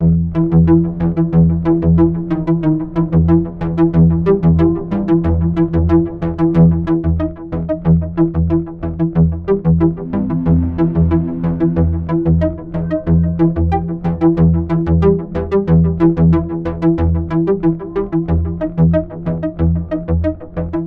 描述：EF调的低音合成器回路
Tag: 92 bpm Industrial Loops Synth Loops 3.51 MB wav Key : E